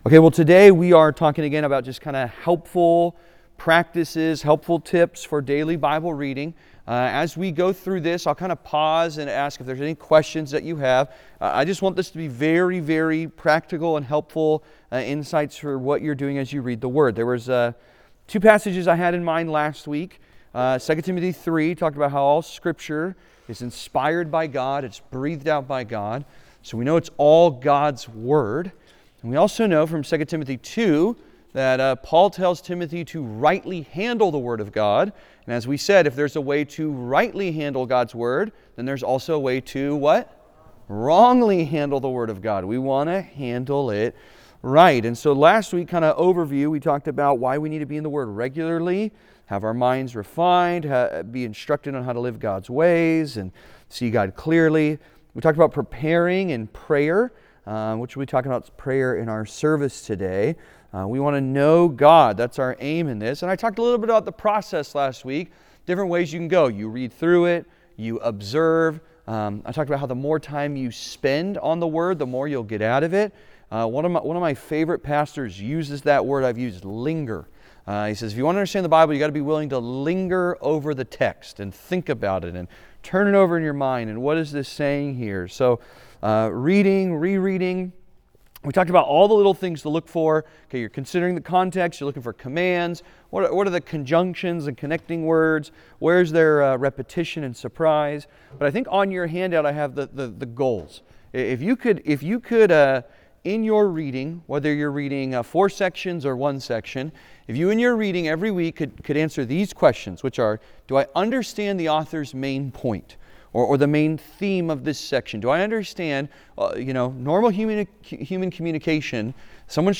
Helpful Practices for Daily Bible Reading Part 2 (Sermon) - Compass Bible Church Long Beach